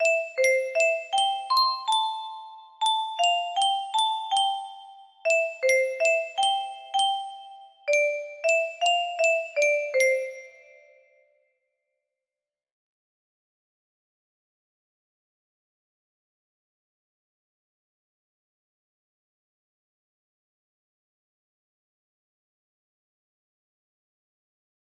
On top of old smokey music box melody